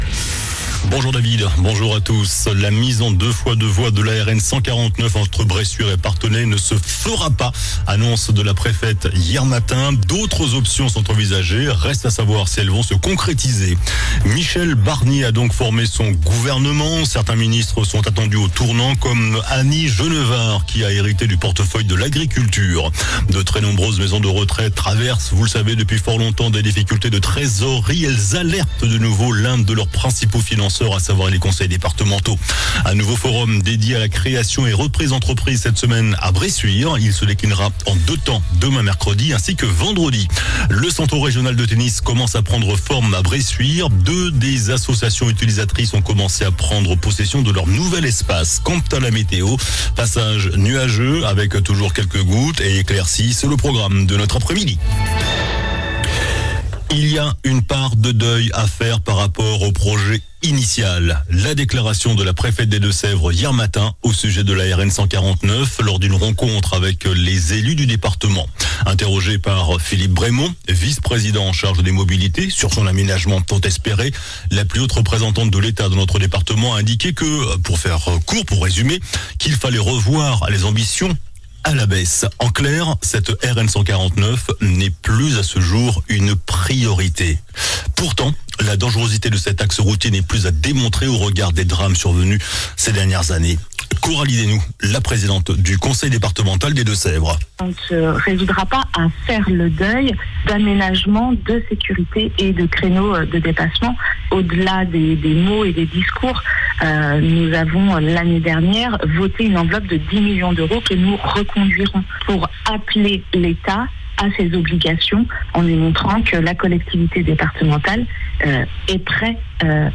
JOURNAL DU MARDI 24 SEPTEMBRE ( MIDI )